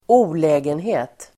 Ladda ner uttalet
olägenhet substantiv, inconvenience Uttal: [²'o:lä:genhe:t] Böjningar: olägenheten, olägenheter Synonymer: besvär, besvärande, obehag Definition: mindre besvär Exempel: sanitär olägenhet (private nuisance)